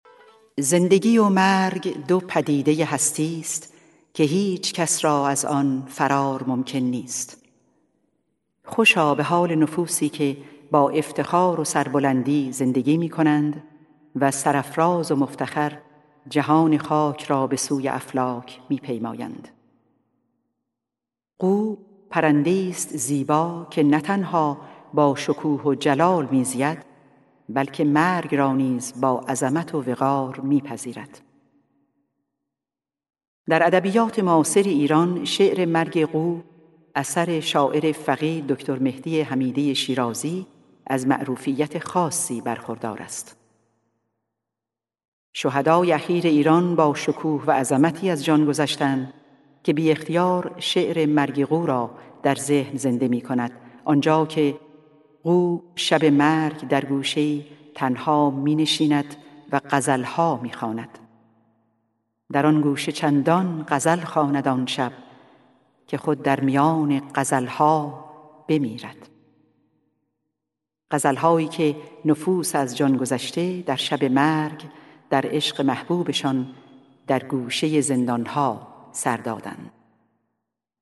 سرود - شماره 10 | تعالیم و عقاید آئین بهائی